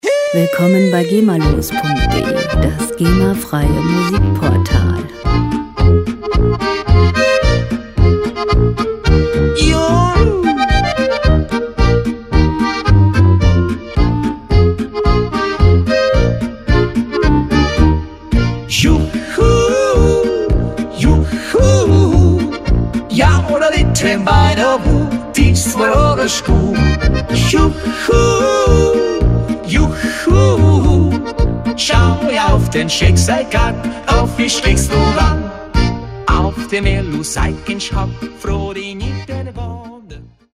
• Alpenländische Volksmusik